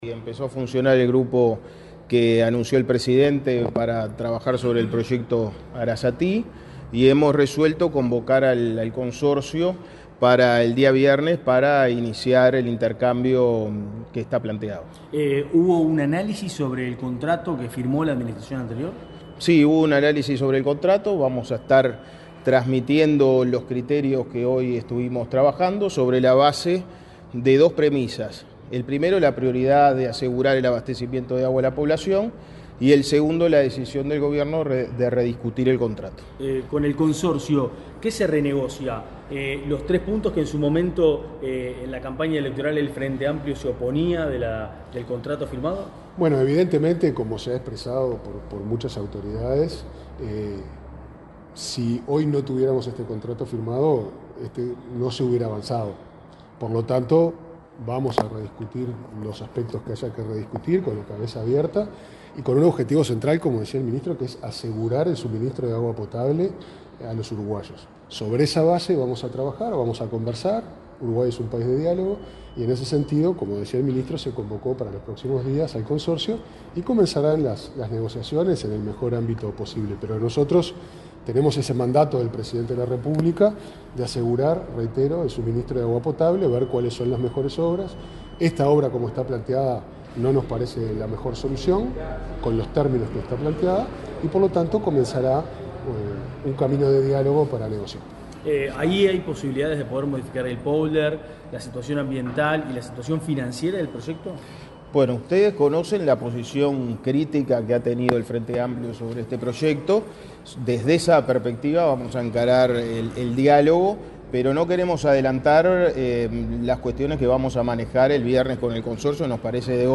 Declaraciones del ministro de Ambiente y el presidente de OSE
Declaraciones del ministro de Ambiente y el presidente de OSE 08/04/2025 Compartir Facebook X Copiar enlace WhatsApp LinkedIn El ministro de Ambiente, Edgardo Ortuño, y el presidente de OSE, Pablo Ferreri, informaron a la prensa, este martes 8 en la Torre Ejecutiva, acerca del grupo que trabajará en el proyecto Arazatí y las reuniones que se mantendrán con el consorcio adjudicatario de la obra.